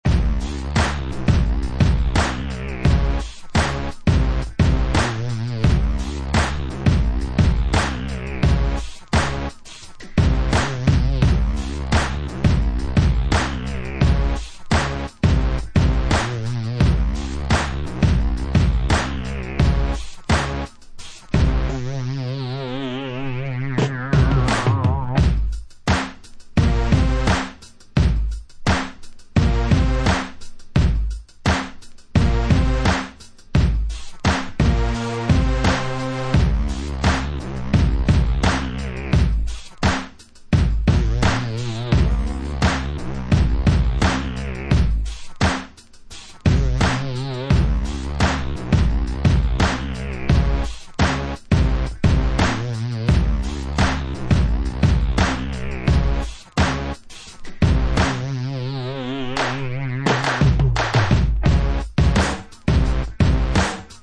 UK Garage / Breaks, Hip Hop/Dj Tools